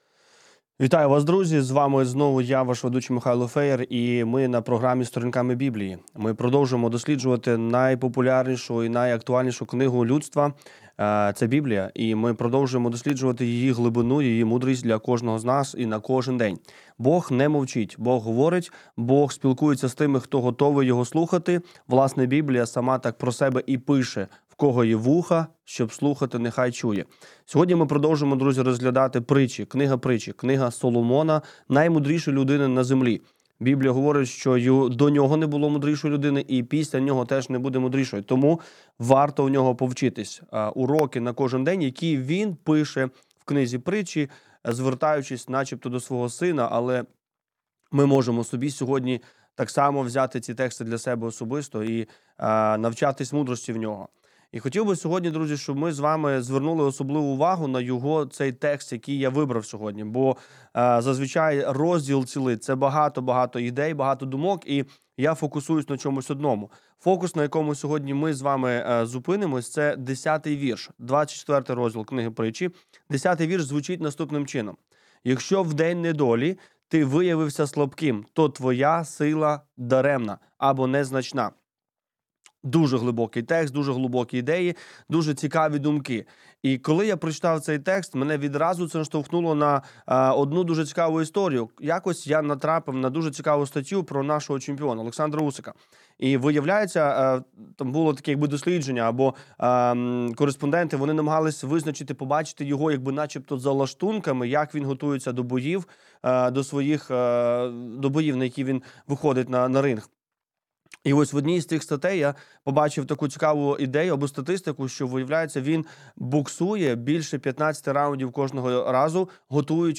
Ефір програми Сторінками Біблії Як приготуватись до труднощів?